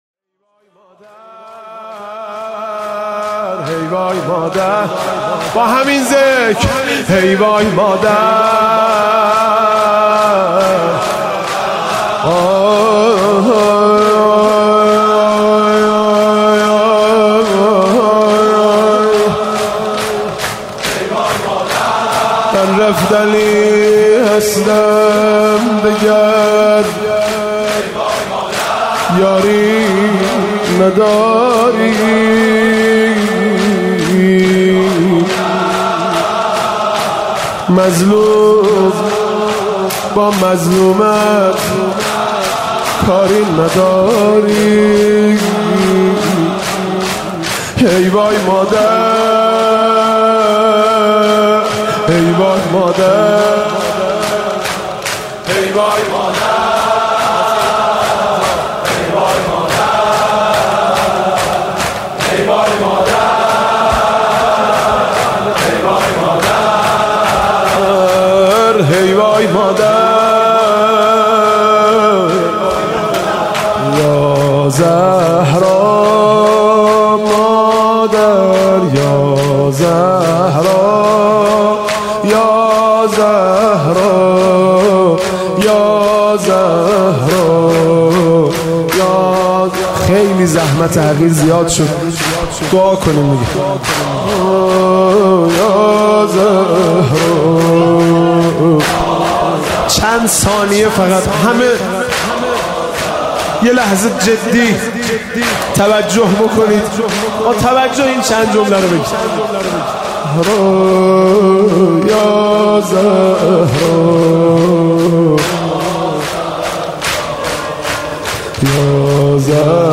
مداحی حاج مهدی رسولی مراسم ایام فاطمیه هیأت میثاق با شهدا دانشگاه امام صادق علیه السلام.